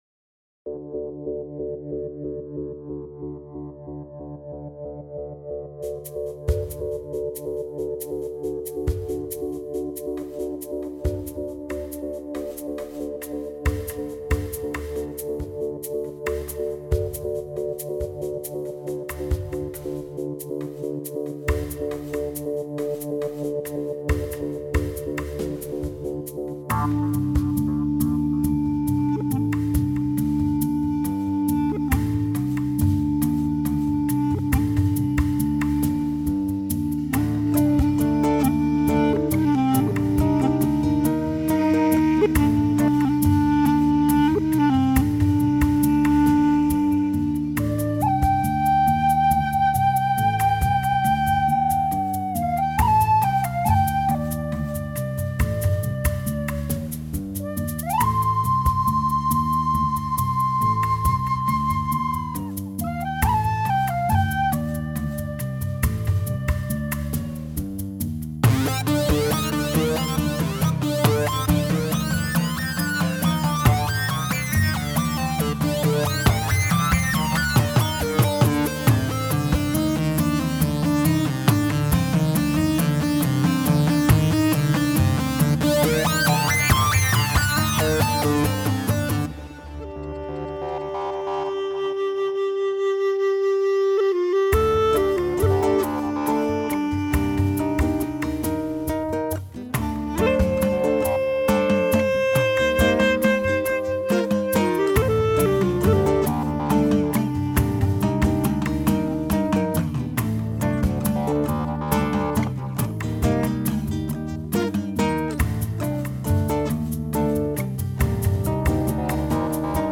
Bollywood Mp3 Music 2015